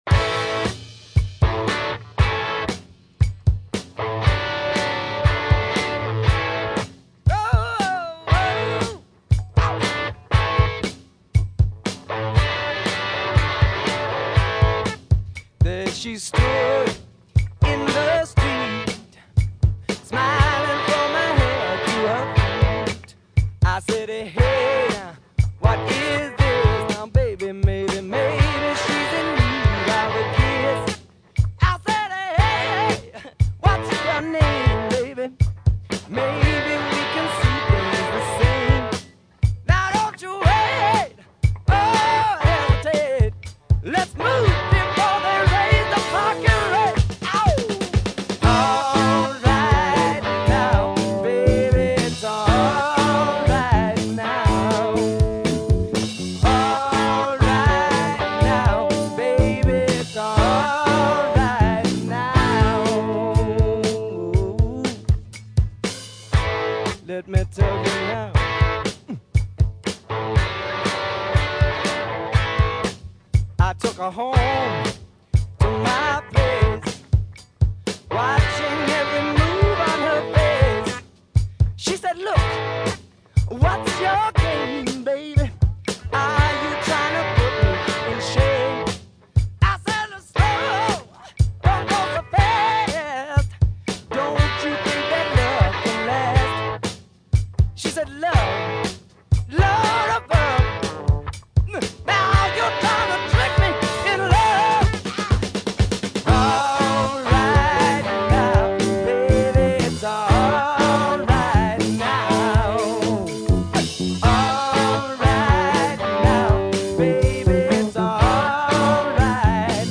Y ahora uno de los himnos imprescindibles de rock and roll.